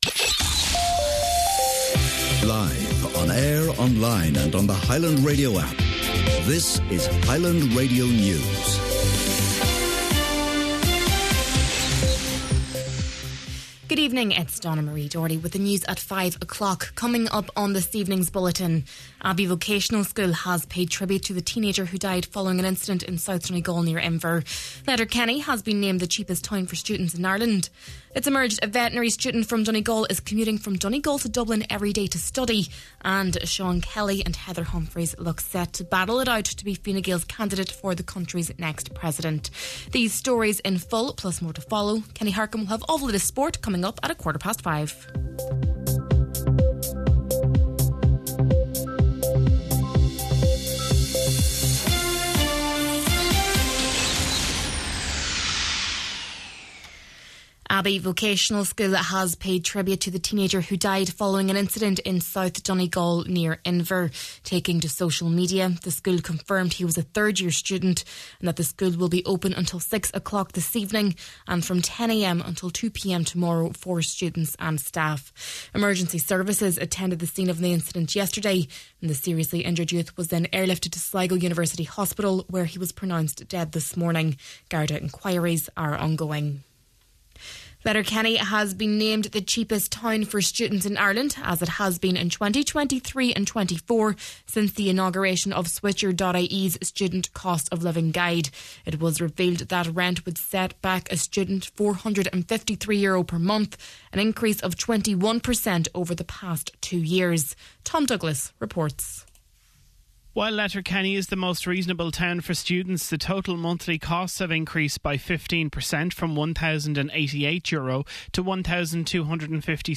News, Sport and Obituary Notices, Tuesday, August 19th